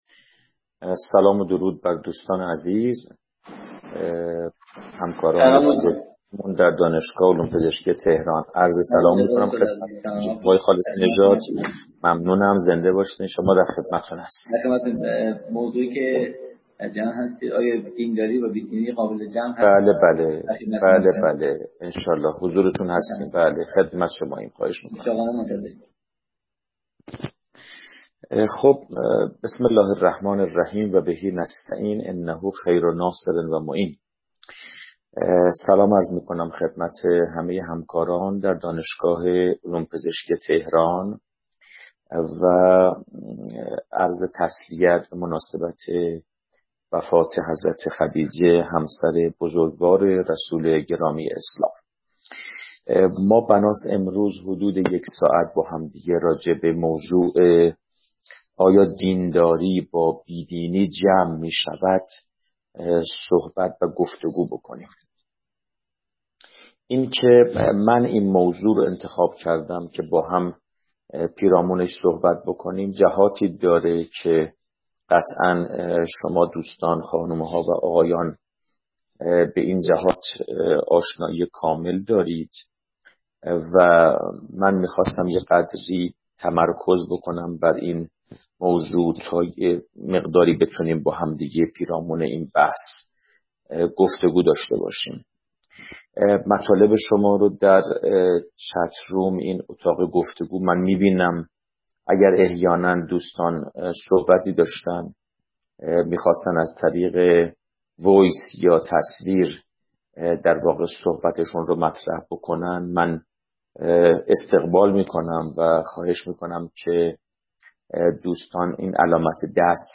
به همت مرکز فرهنگی قرآن و عترت نهاد نمایندگی مقام معظم رهبری، دومین جلسه از سلسله مباحث معرفتی قرآن به مناسبت ماه مبارک رمضان، روز سه‌شنبه 23 فروردین در سامانه اسکای روم دفتر نهاد برگزار شد.